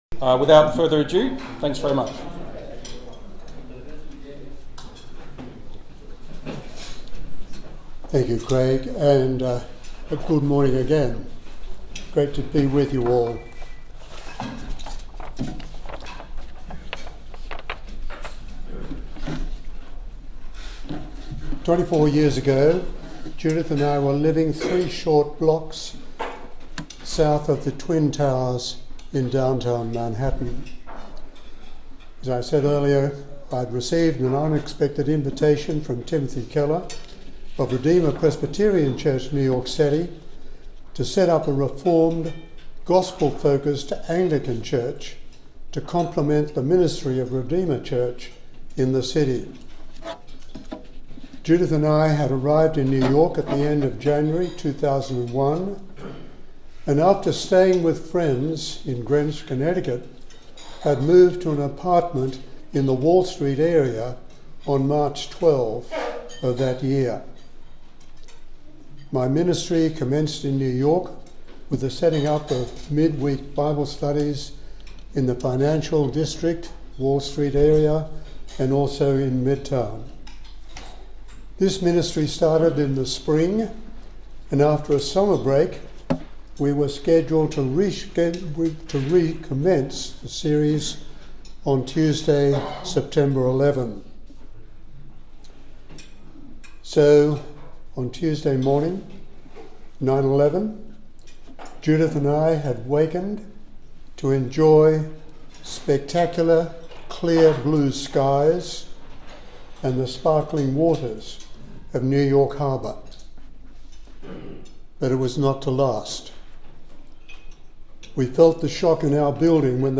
Talks by various speakers given at St Matt's monthly Men's Breakfasts